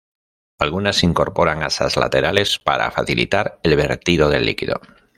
Pronounced as (IPA)
/beɾˈtido/